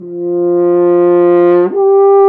Index of /90_sSampleCDs/Roland L-CDX-03 Disk 2/BRS_F.Horn FX/BRS_Intervals
BRS F HRN 0P.wav